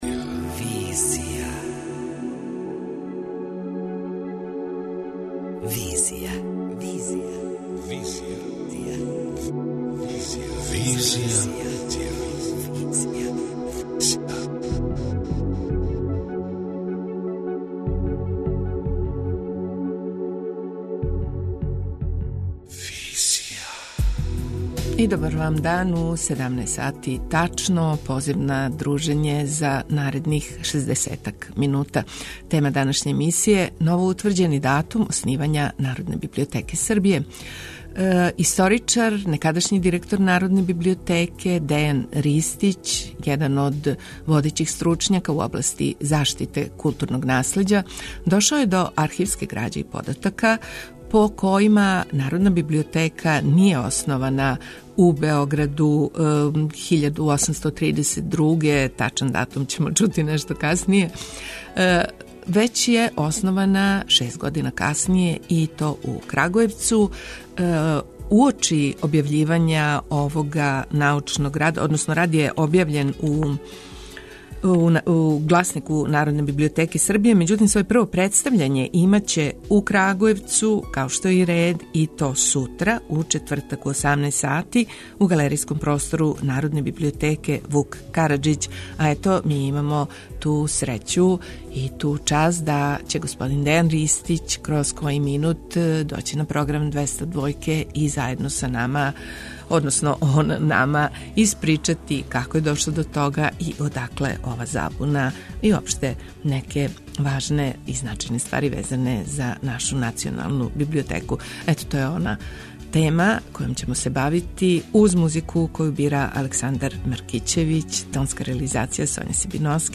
преузми : 27.63 MB Визија Autor: Београд 202 Социо-културолошки магазин, који прати савремене друштвене феномене.